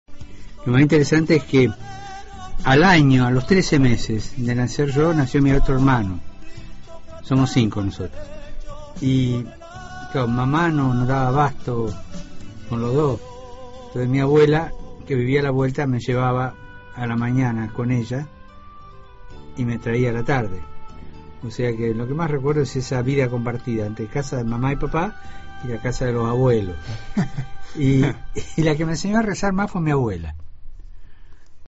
Fragmento de una entrevista que el Papa Francisco concedió hace unos meses a la radio La 96, Voz de Caacupé, emisora de la parroquia Caacupé, en una aldea de Buenos Aires.